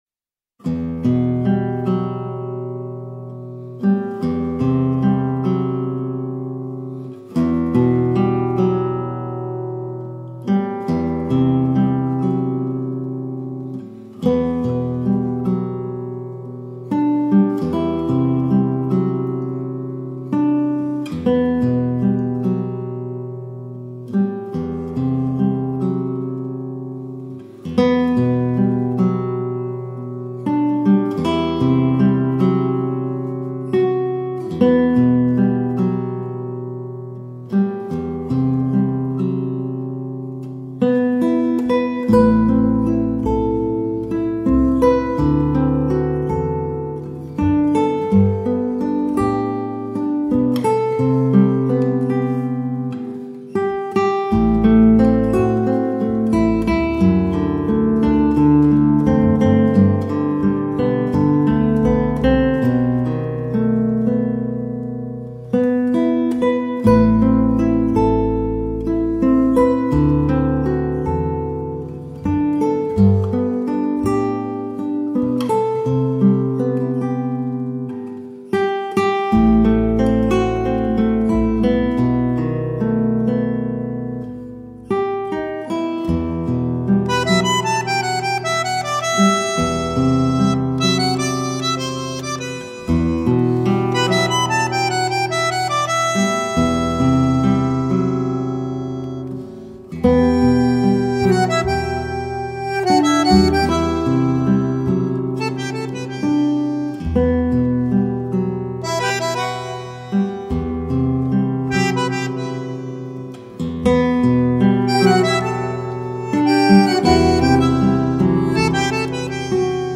gitaar